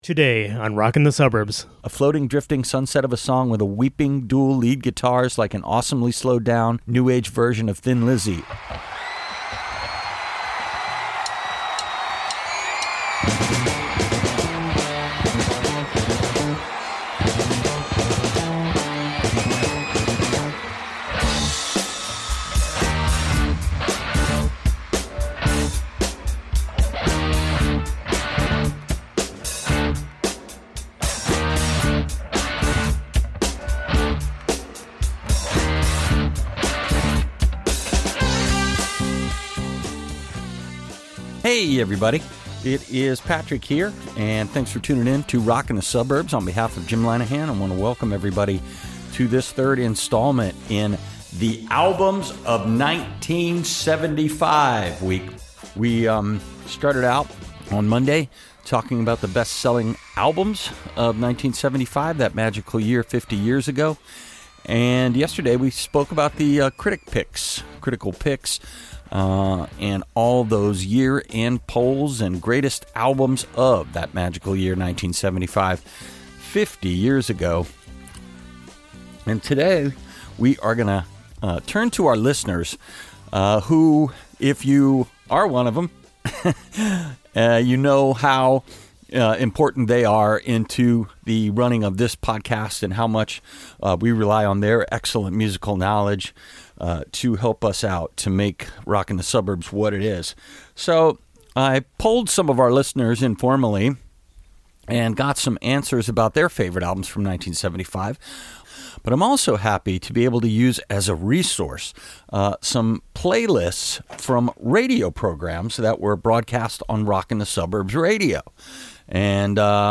Our listeners take center stage in the third installment of the series, chiming in with some of their fa...